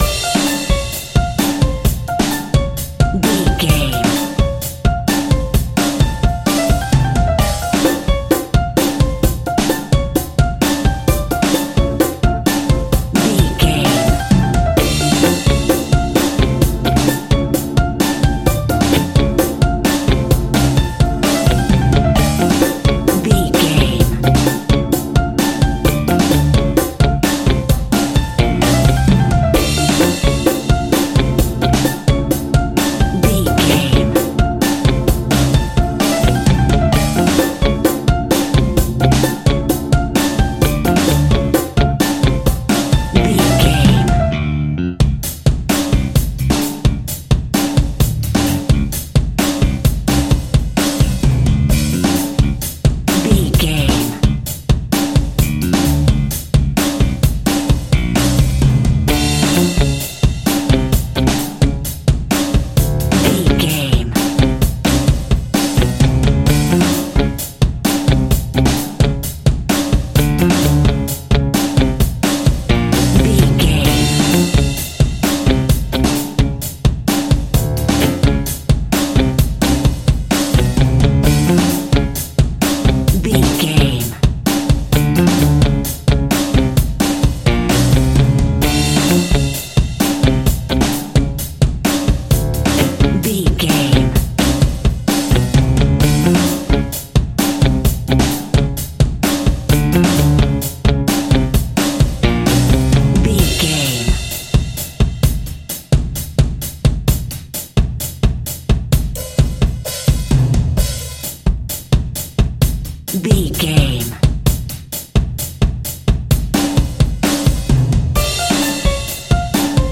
Aeolian/Minor
flamenco
maracas
percussion spanish guitar
latin guitar